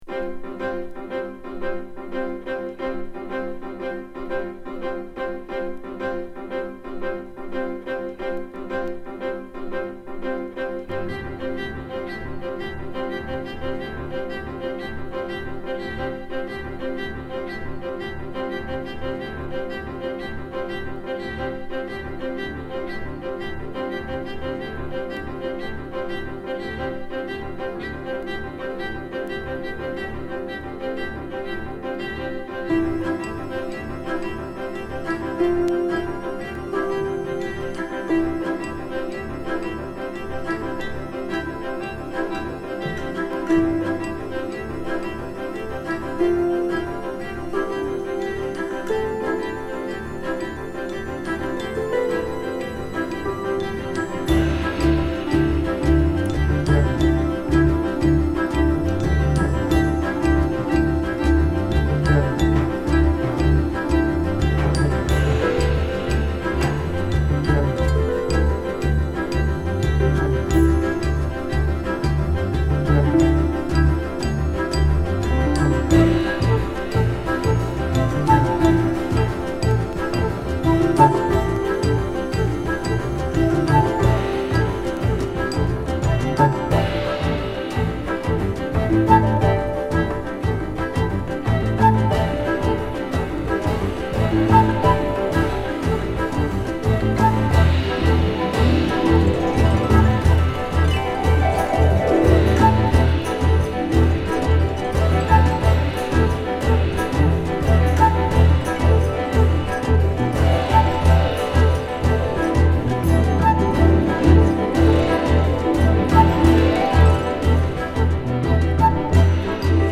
最初のロックダウンが始まる僅か数日前に16人のミュージシャンが一同に会した二日間の記録。